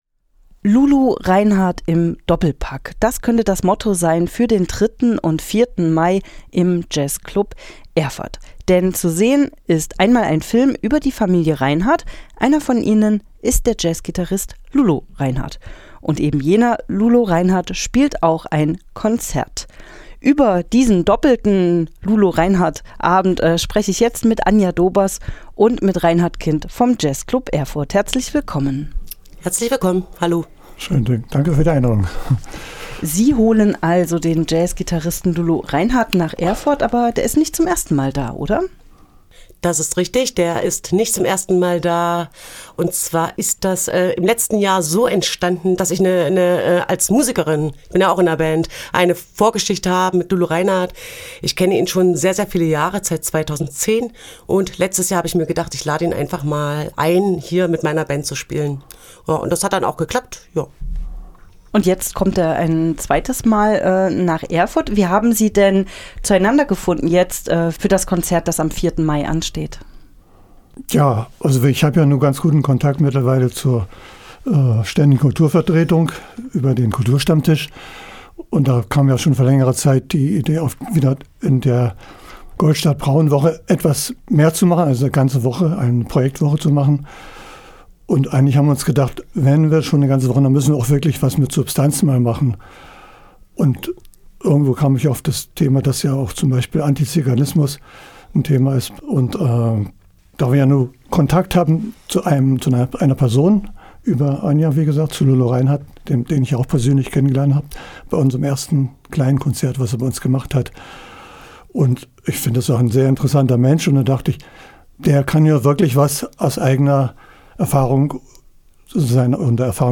| Interview mit dem Jazzclub über Lulo Reinhardt